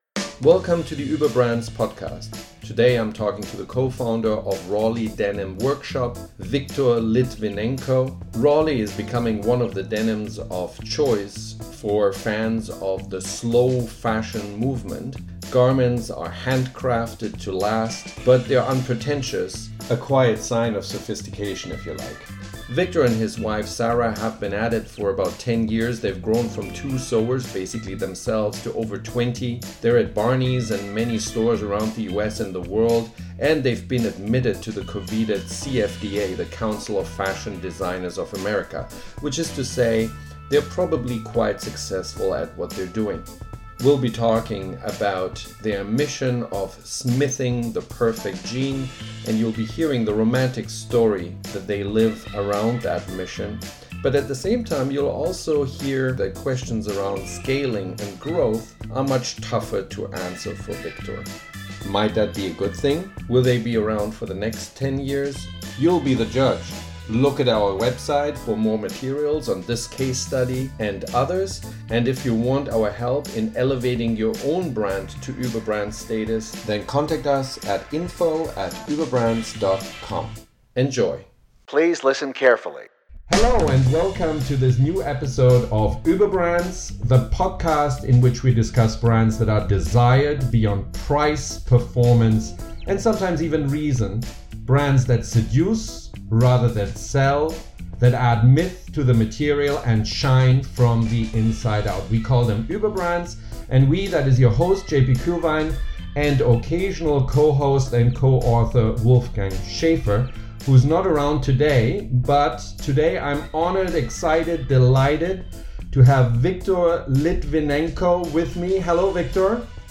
Making Slow Fashion Grow – Interview